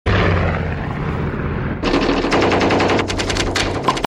• MACHINE GUN FIRE WITH PLANE.wav
MACHINE_GUN_FIRE_WITH_PLANE_TXq.wav